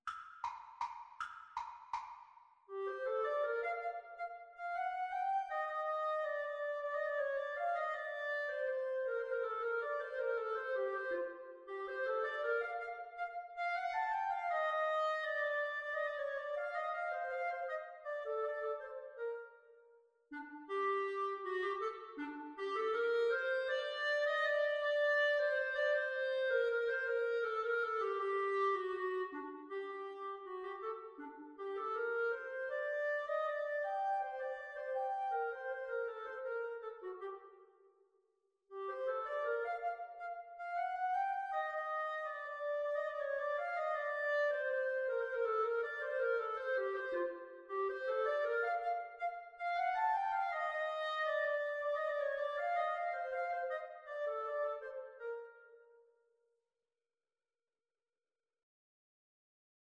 3/4 (View more 3/4 Music)
Non troppo presto
Bb major (Sounding Pitch) C major (Clarinet in Bb) (View more Bb major Music for Clarinet Duet )
Classical (View more Classical Clarinet Duet Music)